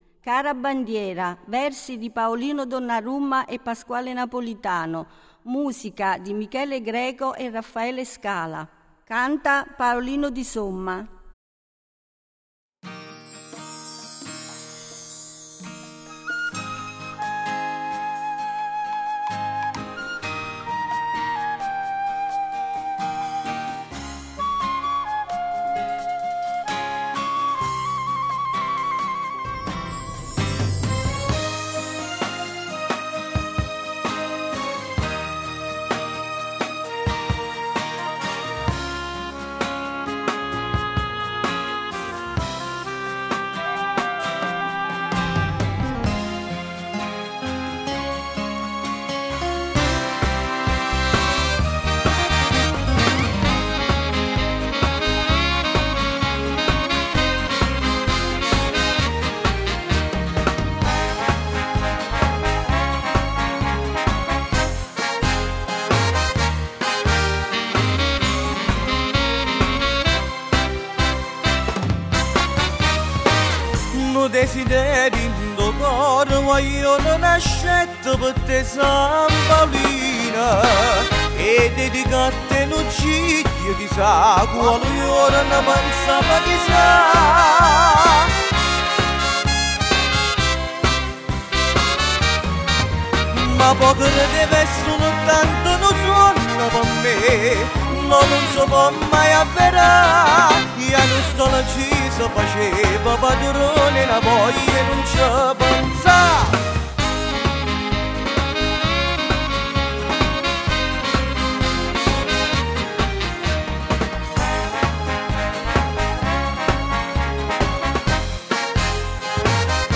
Giglio del Panettiere 2009